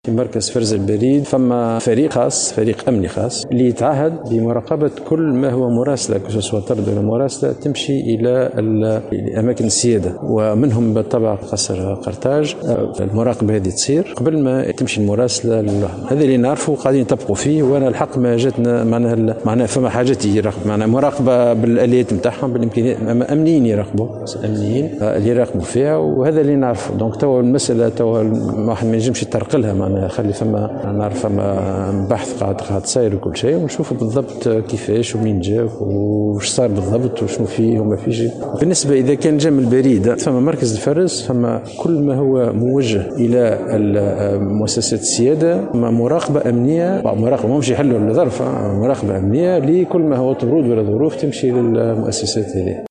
أكد وزير تكنولوجيا الاتصال محمد الفاضل كريم في تصريح لمراسلة الجوهرة "اف ام" اليوم الخميس وجود فريق أمني خاص تُعهد له مراقبة كل ما هو طرد أو مراسلة تذهب لمقرات السيادة ومنها قصر قرطاج حسب قوله .